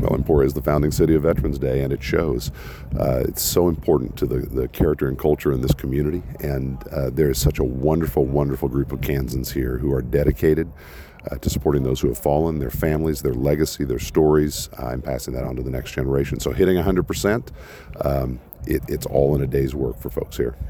This was an extreme point of pride for organizers and local and state leaders who attended the annual Wreaths Across America ceremony inside Maplewood Memorial Lawn Cemetery Saturday morning, including Kansas Congressman Derek Schmidt, who tells KVOE News he was not surprised that the founding city of Veterans Day managed to come together to properly honor the memory of more than 2,300 veterans.